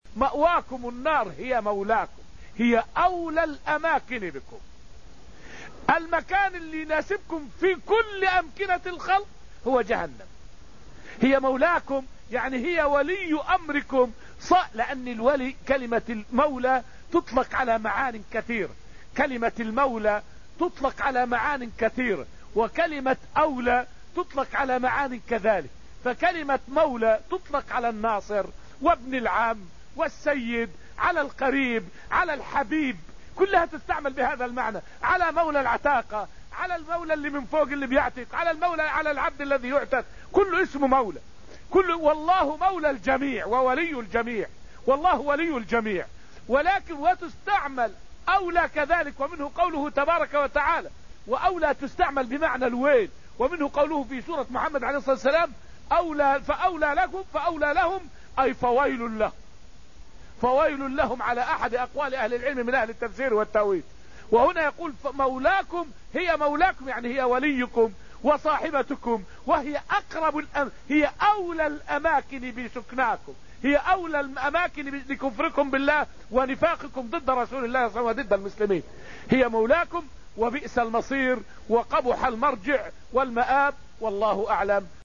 فائدة من الدرس السادس عشر من دروس تفسير سورة الحديد والتي ألقيت في المسجد النبوي الشريف حول معنى قوله تعالى: {مأواكم النار هي مولاكم}.